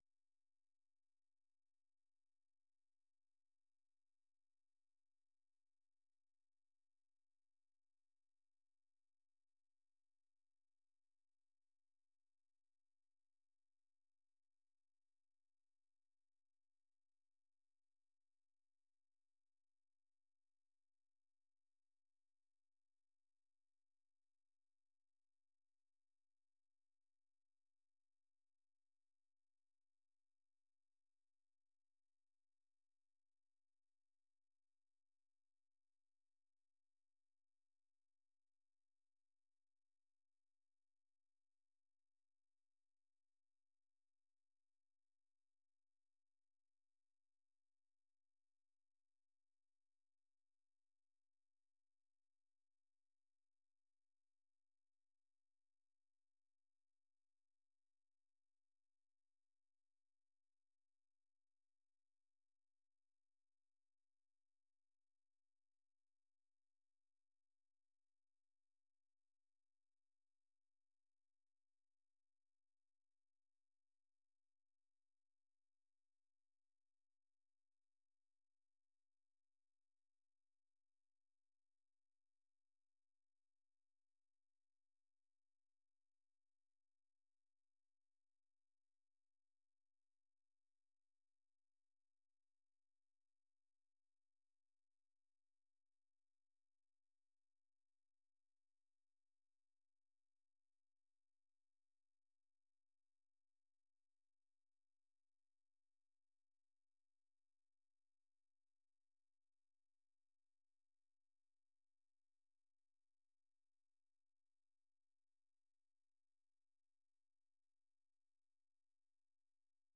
The URL has been copied to your clipboard 페이스북으로 공유하기 트위터로 공유하기 No media source currently available 0:00 0:59:58 0:00 생방송 여기는 워싱턴입니다 생방송 여기는 워싱턴입니다 저녁 공유 생방송 여기는 워싱턴입니다 저녁 share 세계 뉴스와 함께 미국의 모든 것을 소개하는 '생방송 여기는 워싱턴입니다', 저녁 방송입니다.